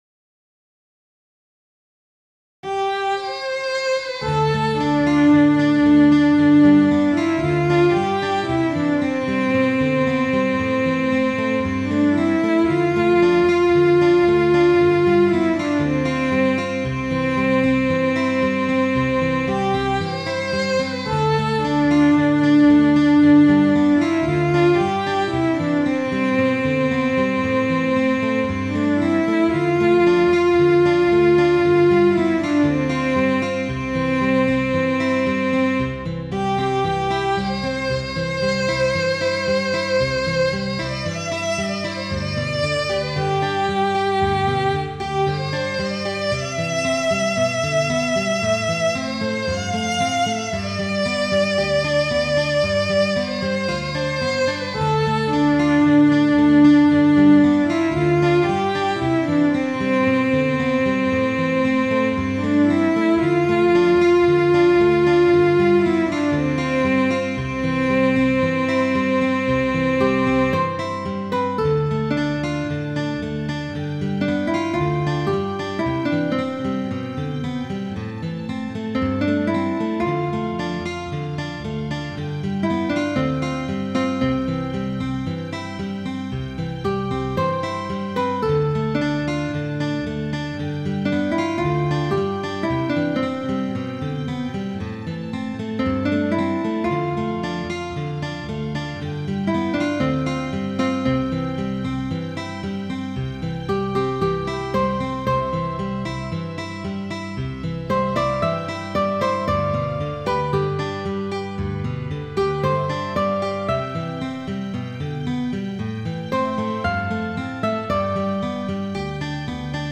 carrig.mid.ogg